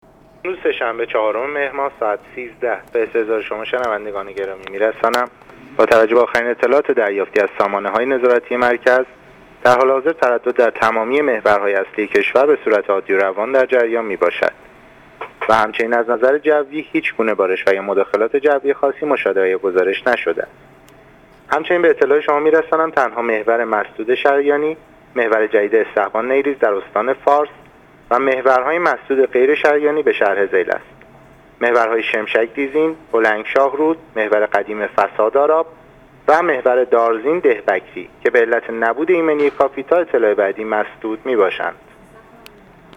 رادیو اینترنتی پایگاه خبری وزارت راه و شهرسازی: